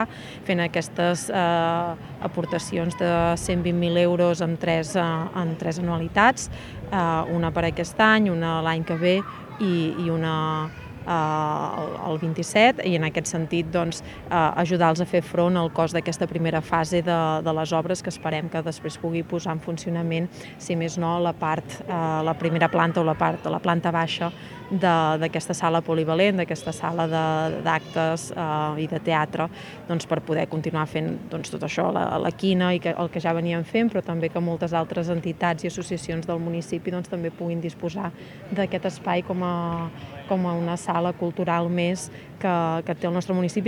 Són declaracions de l’alcaldessa de Palafrugell, Laura Millán.